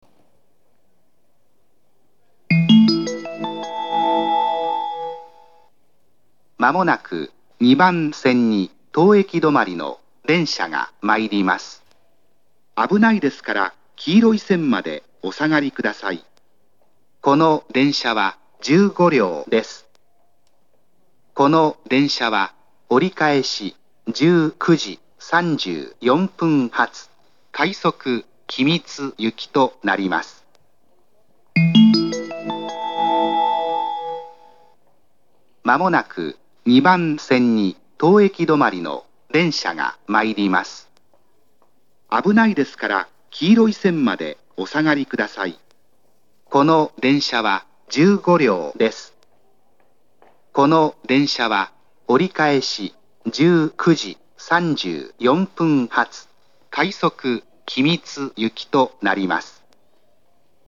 ２番線接近放送
横須賀線・副線ホームです。
tokyo-sobu2bansen-sekkin.mp3